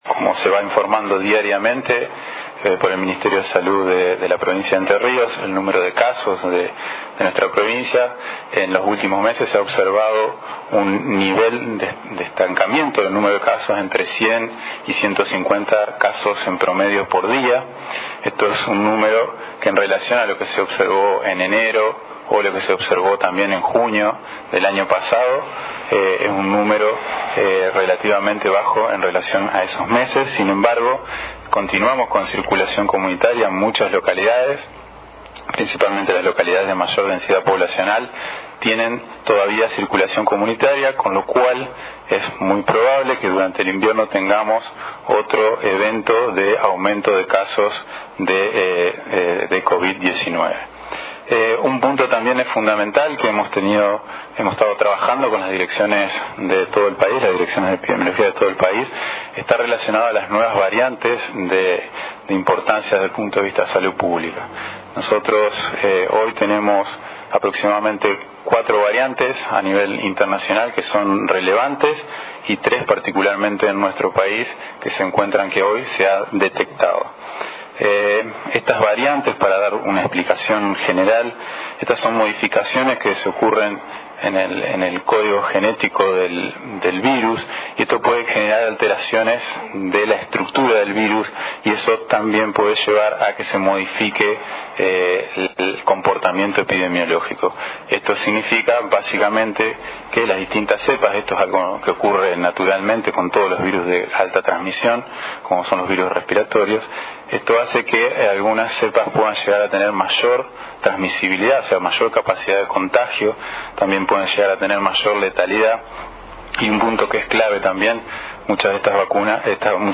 A través de una conferencia de prensa virtual, el Director provincial de Epidemiología, Doctor Diego Garcilazo, habló sobre dos temas que atraviesan, el operativo con el coronavirus, que lleva adelante esta gestión provincial.